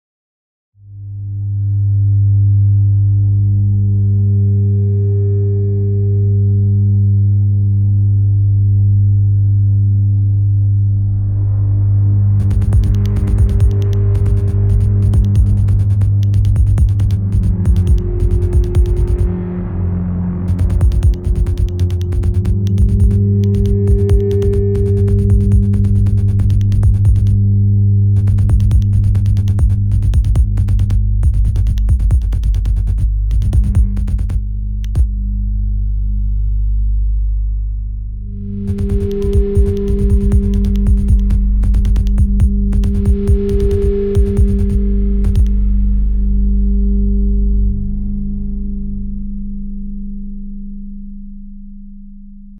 Minimal Electro